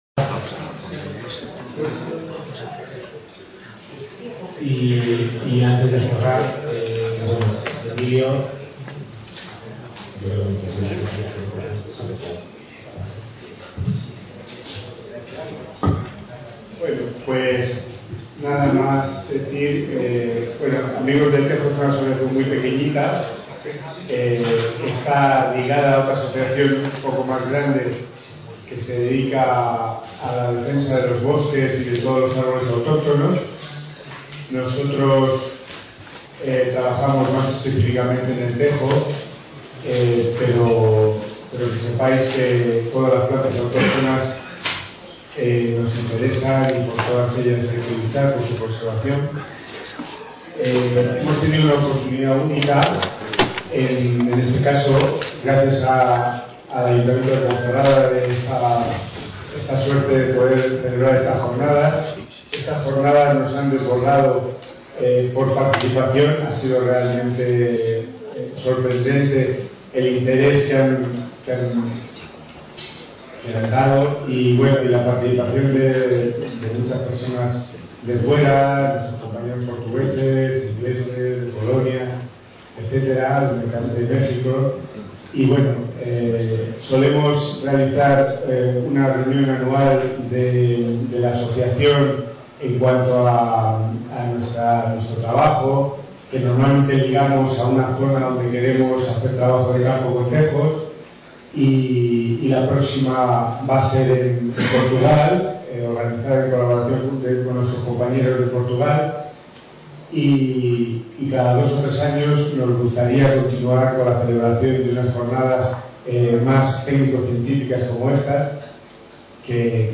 Acto Clausura - III Jornadas Internacionales del Tejo
| Red: UNED | Centro: UNED | Asig: Reunion, debate, coloquio...